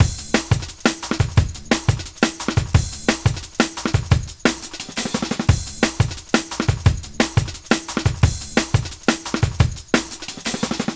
Index of /neuro/Stanza/Drums/Drum Loops